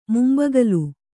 ♪ mumbagalu